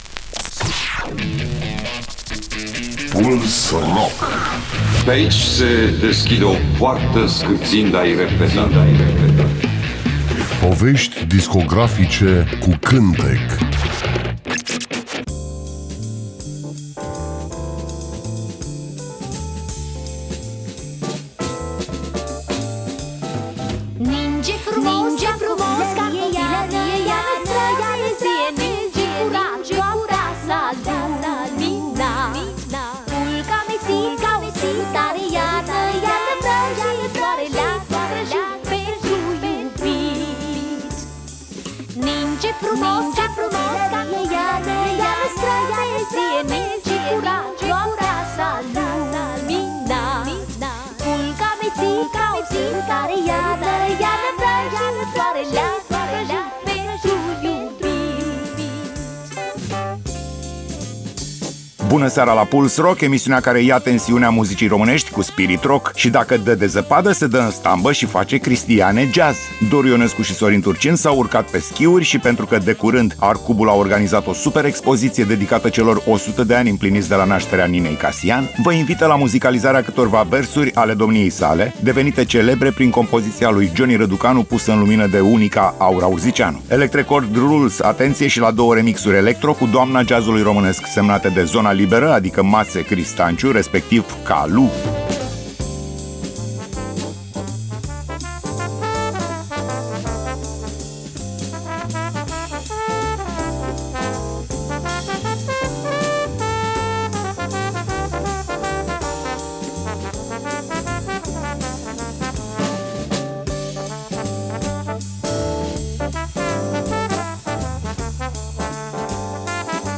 Emisiunea se numește Puls Rock și jonglează cu artiștii noștri rock, folk, uneori chiar jazz. Voi căuta lucruri interesante, valoroase, care au scăpat atenției publicului. Fiecare melodie pe care o voi prezenta are o poveste.